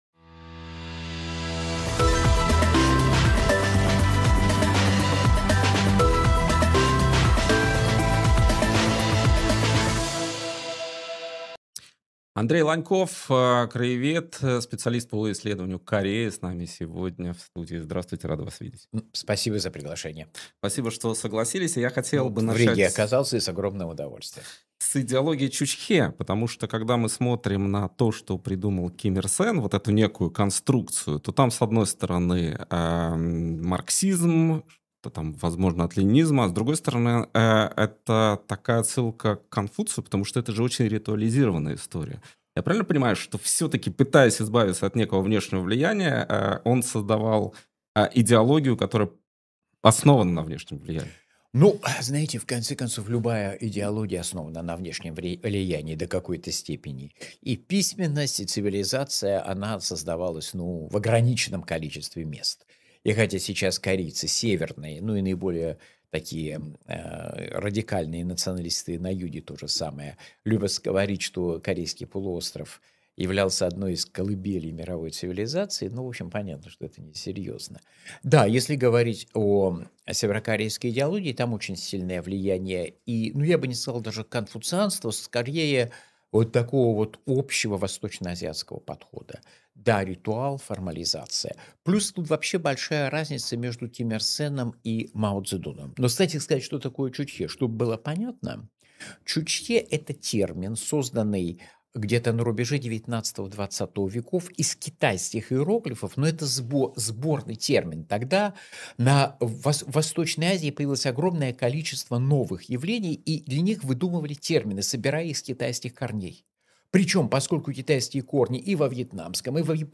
Андрей Ланьков кореевед, профессор университета Кунмин